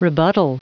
Prononciation du mot rebuttal en anglais (fichier audio)
Prononciation du mot : rebuttal